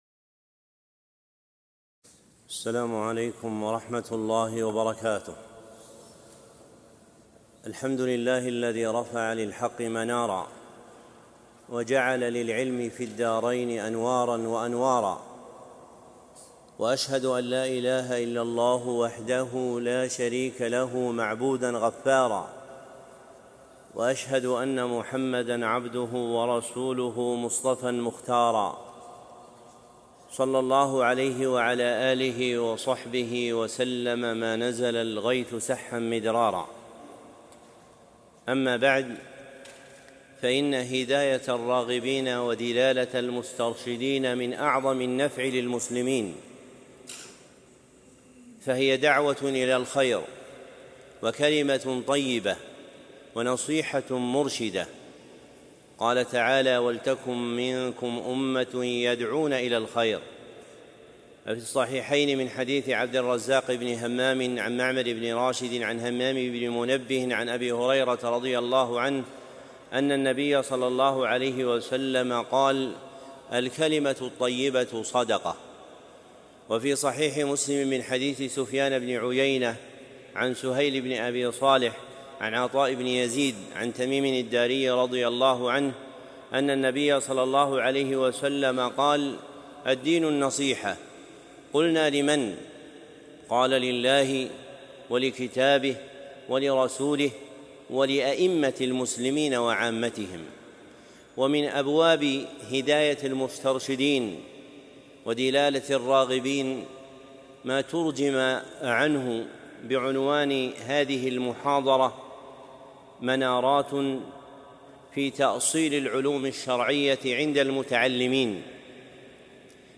محاضرة منارات في تأصيل العلوم الشرعية